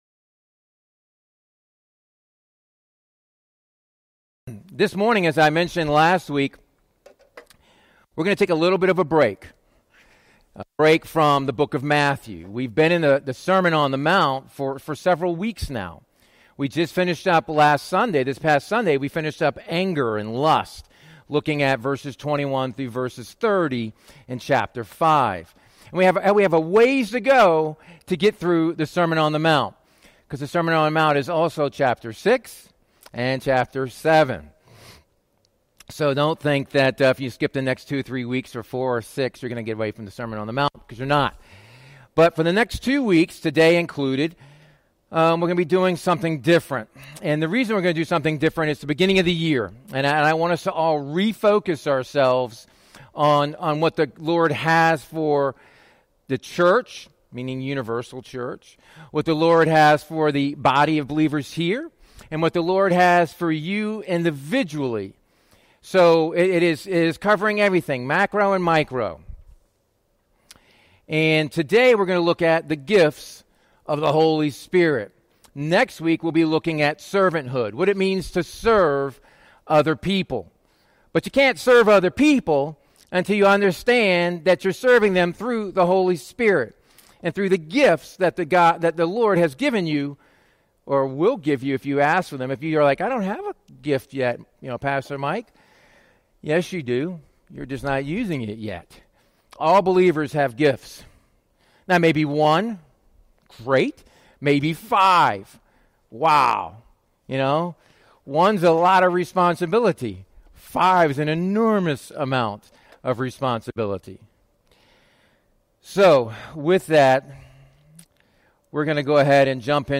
Sermons | Proclaim Christ Church